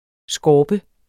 Udtale [ ˈsgɒːbə ]